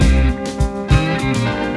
beat.aiff